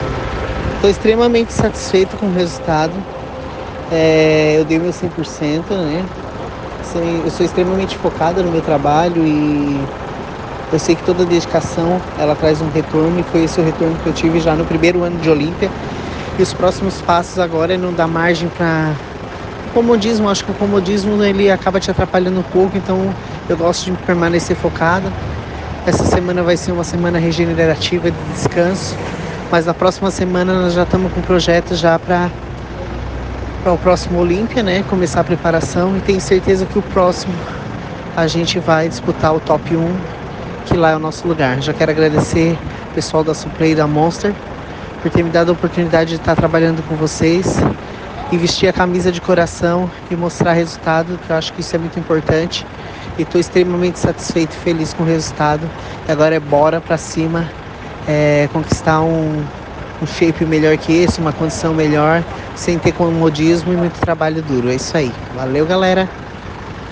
Essa semana vai ser uma semana regenerativa de descanso, mas na próxima semana nós já estamos com projetos para o próximo Olympia.